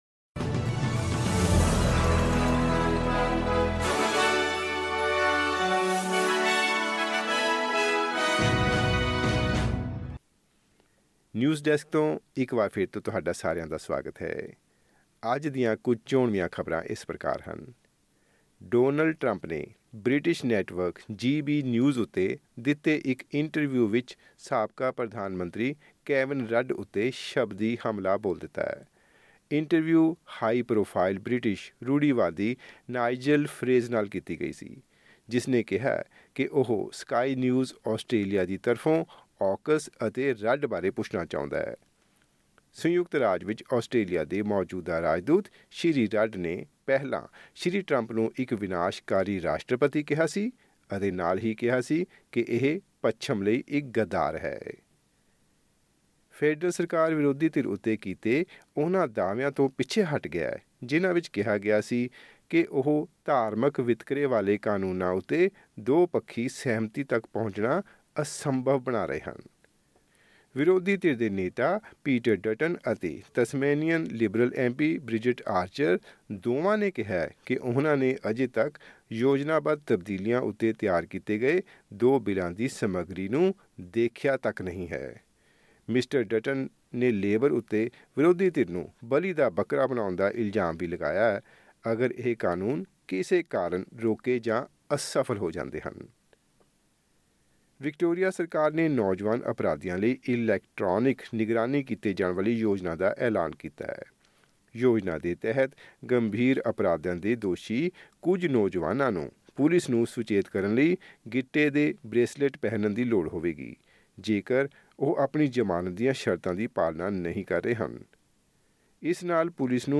ਐਸ ਬੀ ਐਸ ਪੰਜਾਬੀ ਤੋਂ ਆਸਟ੍ਰੇਲੀਆ ਦੀਆਂ ਮੁੱਖ ਖ਼ਬਰਾਂ: 20 ਮਾਰਚ, 2024